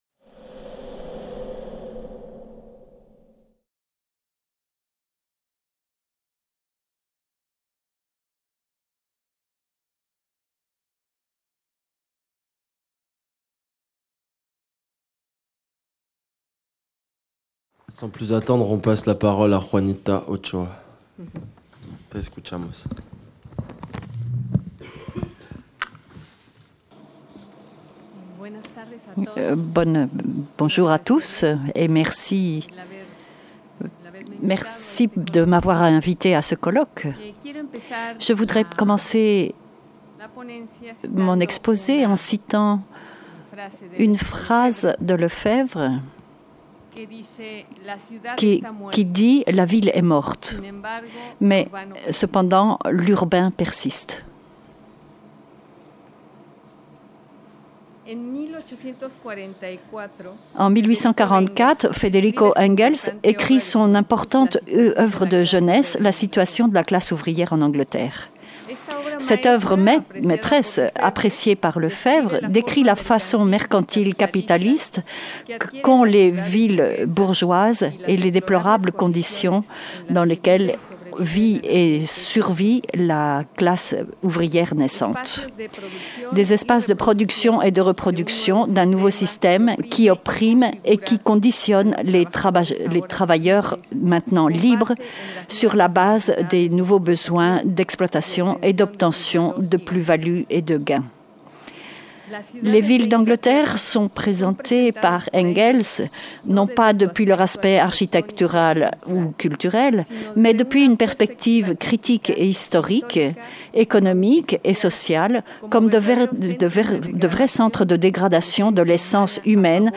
Cette communication a été filmée lors du colloque international "Le droit à Lefebvre" qui s'est déroulé du 29 au 31 mai 2018 à Caen.